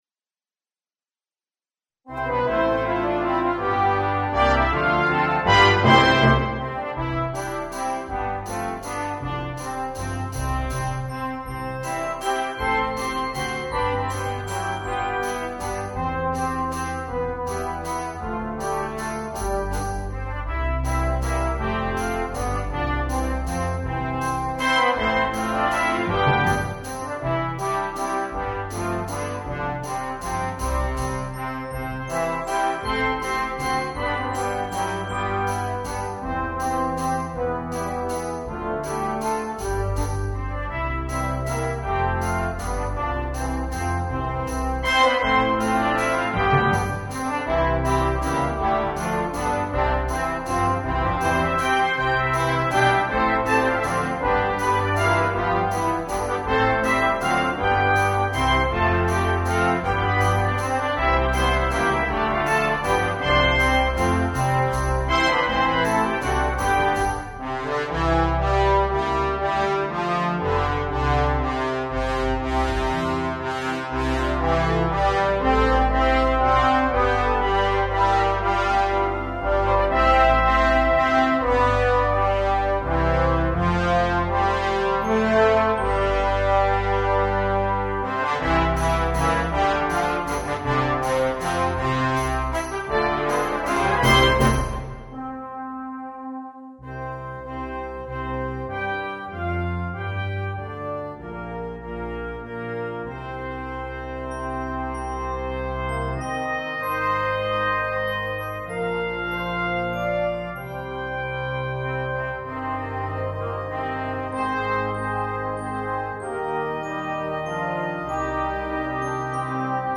Brass Band Score and Parts PDF